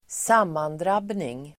Uttal: [²s'am:andrab:ning]